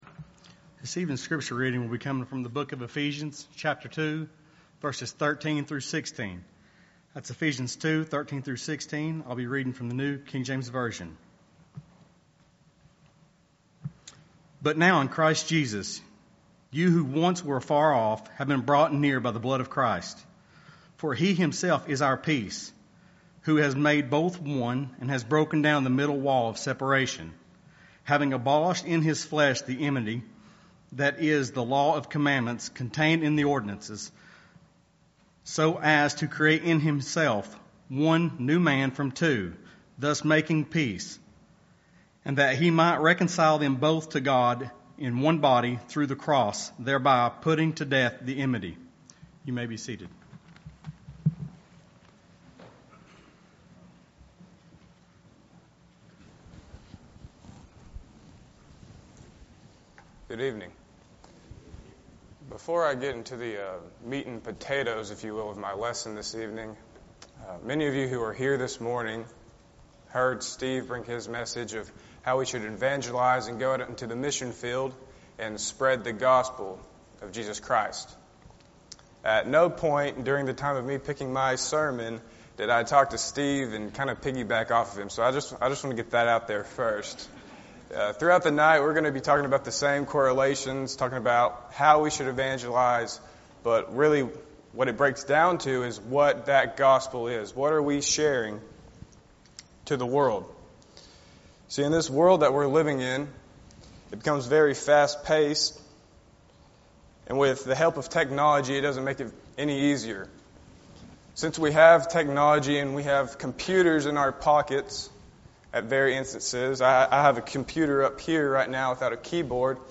Eastside Sermons Passage: Ephesians 2:13-16 Service Type: Sunday Evening « Keep the Faith…but Not to Yourselves How’s Your Interest?